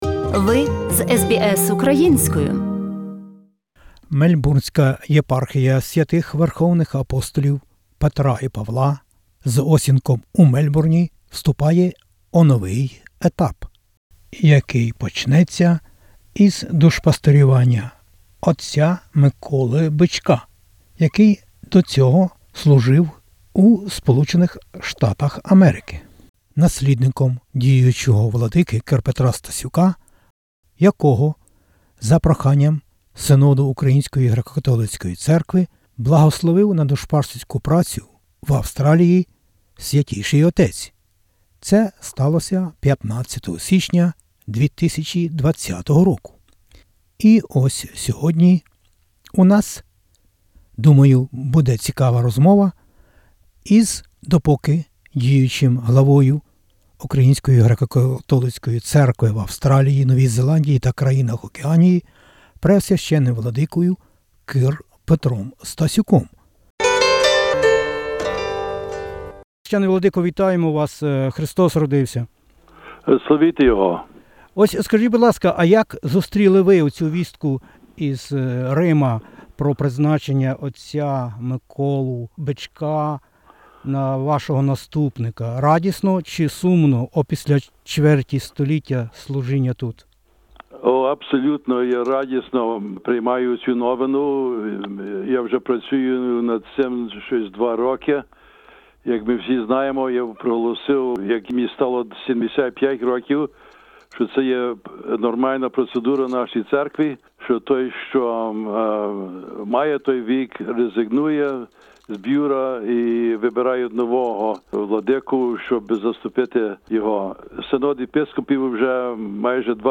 розмовляє із орденоносцем Ордена Австралії Преосв. Владикою Кир Петром Стасюком, Главою УГКЦ в Австралії, Новій Зеландії та країнах Океанії. Moва - про епоху, яка минає, багато важливого і цікавого...